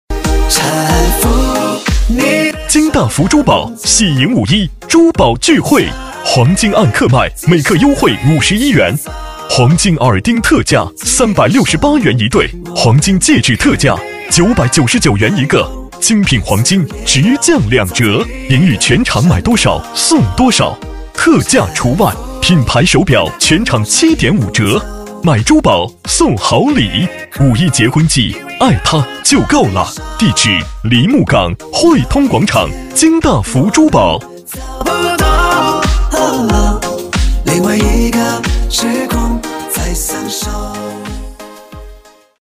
【男60号促销】金大福珠宝.mp3